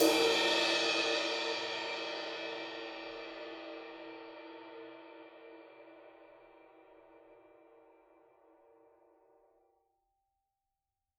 susCymb1-hitstick_fff_rr2.wav